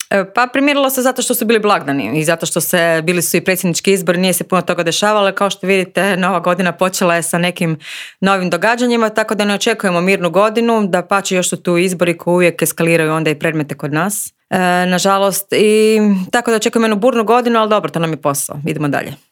Prošle godina Novaković je, kao i cijelo Povjerenstvo, bila pod velikim pritiskom, poglavito političkim. Na pitanje je li se sad situacija malo primirila, odgovorila je: